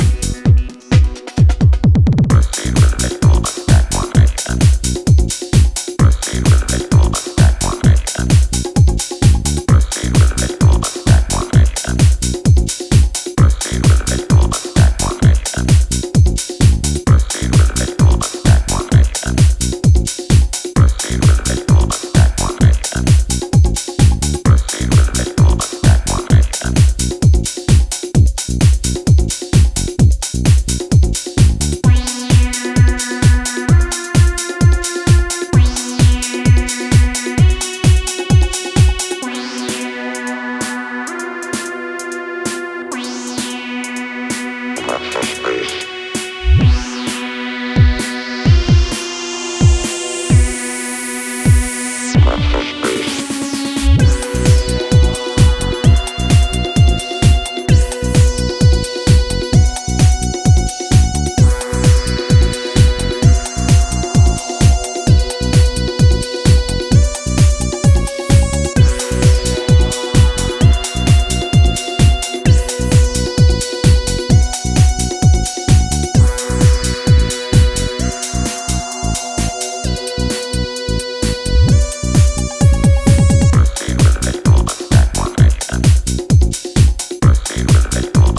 blending deep rhythm, swing, and hypnotic warmth.